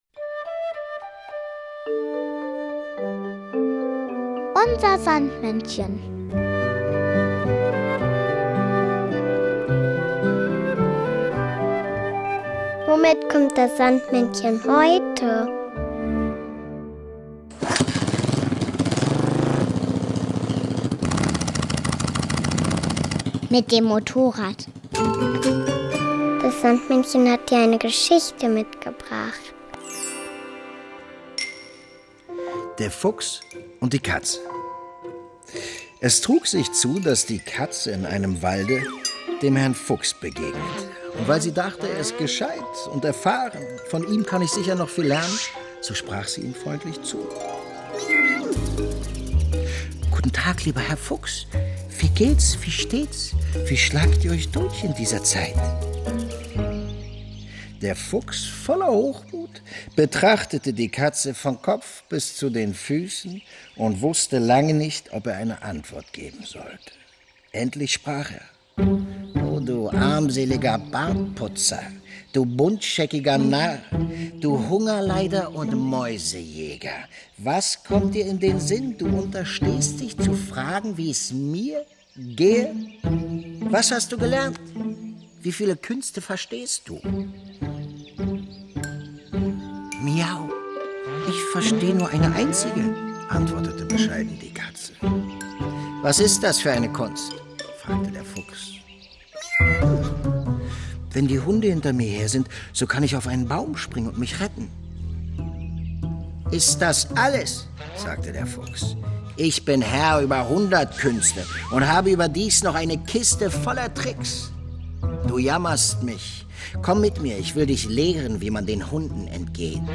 Der Schauspieler Benno Fürmann erzählt eine Geschichte.